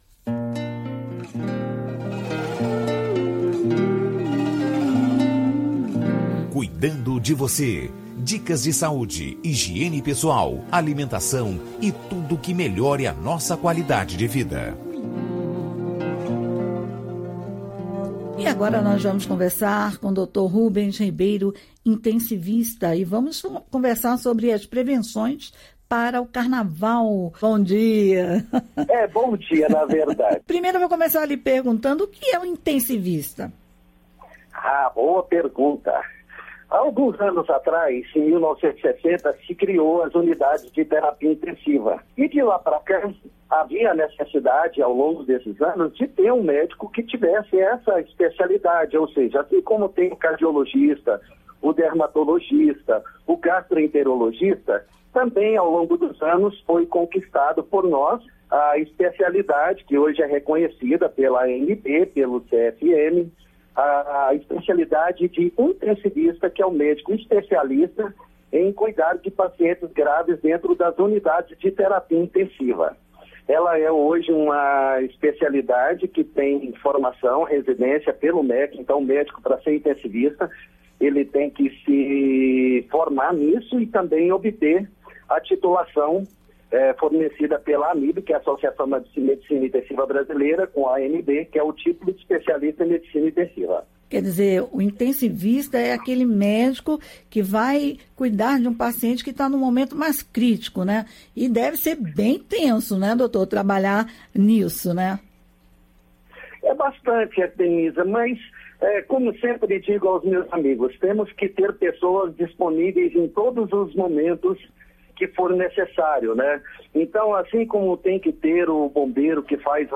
Em entrevista ao programa Amazônia Brasileira